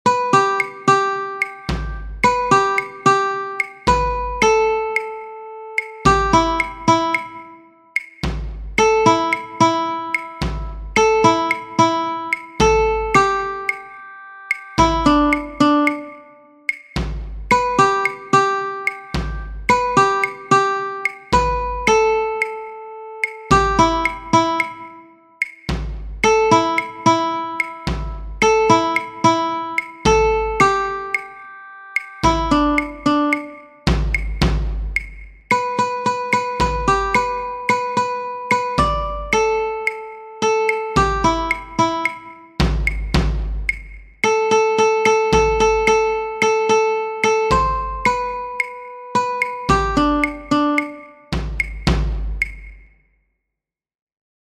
Qongqothwane (The Click Song) is a traditional South African song which is sung by the Xhosa people, an ethnic group who settled in South Africa and all Southern Africa.
It is also called "The Click Song" due to the clicks that the Xhosa language has, three different types of sounds represented with the letters C, X, and Q in combination with vowels.